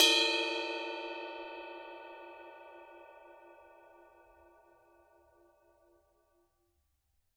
susCymb1-hit-bell_pp.wav